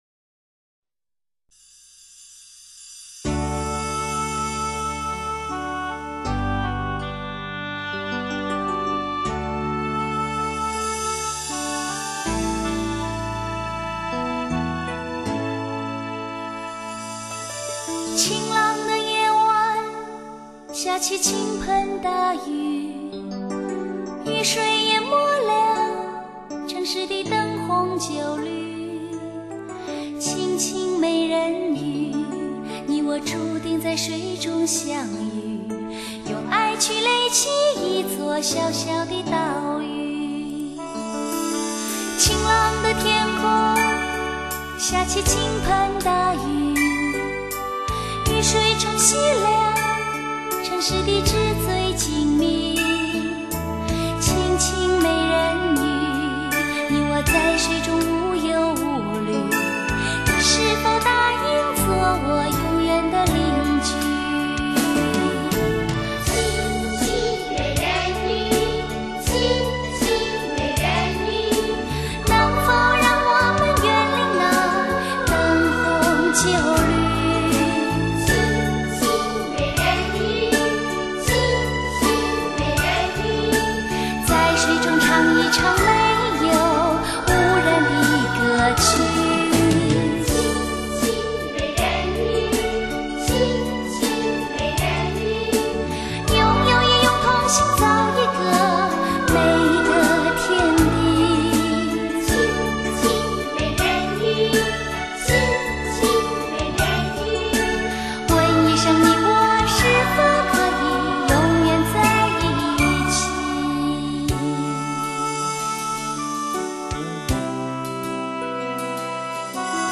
她的声音好甜，令人陶醉。